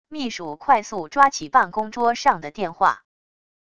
秘书快速抓起办公桌上的电话wav音频